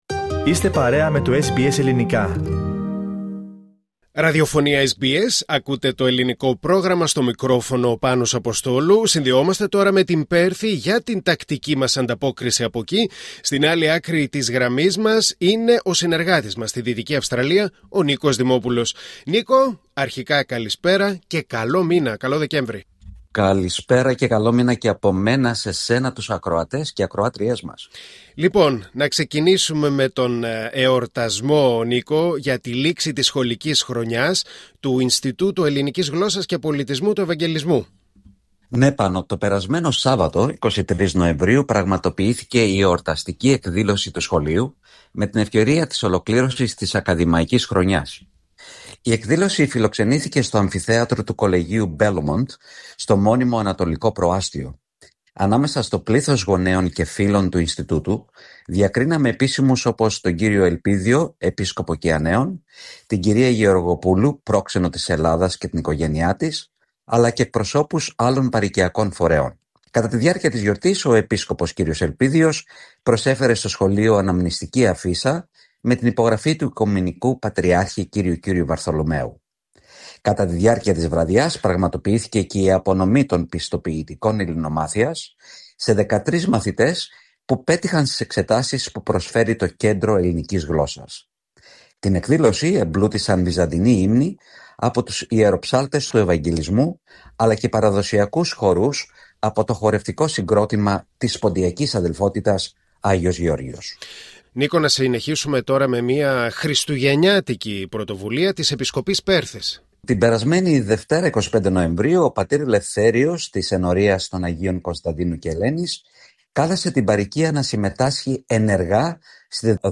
Ακούστε την εβδομαδιαία ανταπόκριση από την Δυτική Αυστραλία